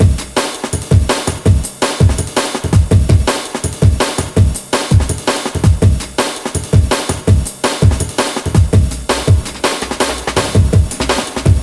霹雳鼓
描述：用FL Studio制作 165 BPM
Tag: 165 bpm Breakbeat Loops Drum Loops 1.96 MB wav Key : Unknown